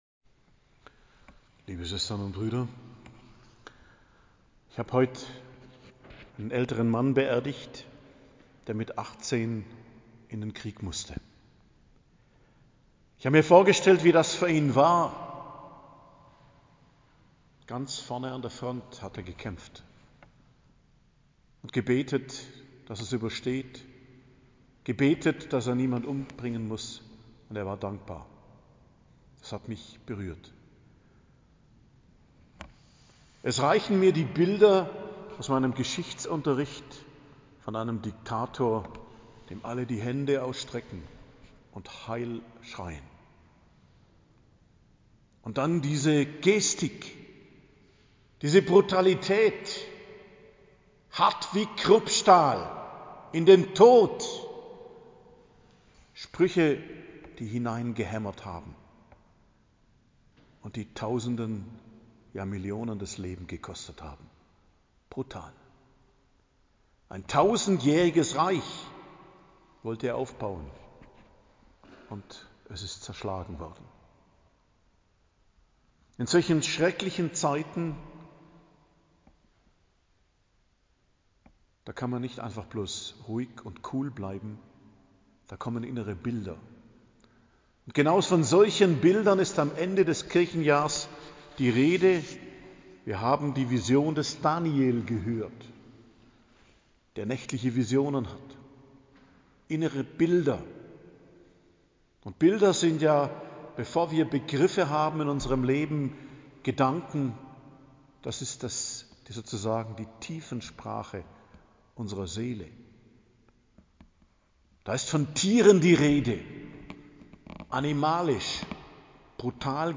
Predigt am Freitag der 34. Woche im Jahreskreis, 26.11.2021